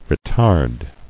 [re·tard]